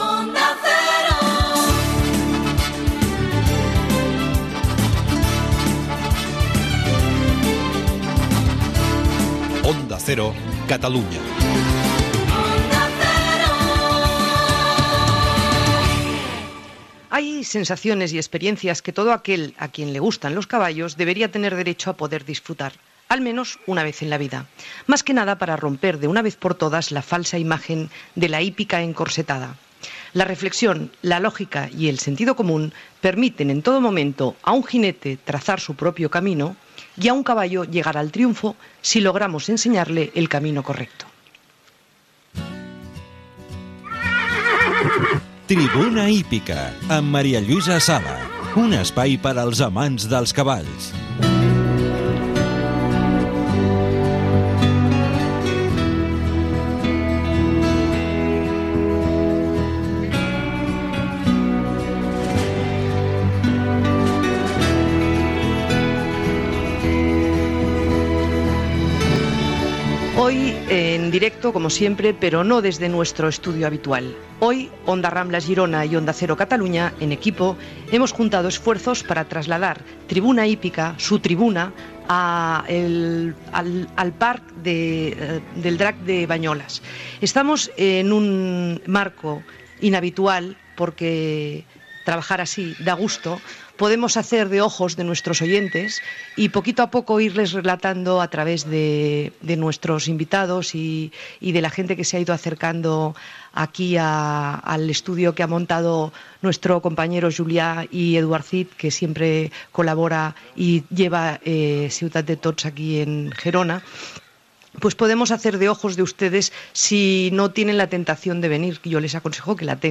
Indicatiu de la cadena, presentació, careta, espai fet des de la fira Equnova, celebrada a Banyoles .L'esport del Horse Ball